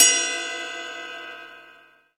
Crashes & Cymbals
Ride_02.wav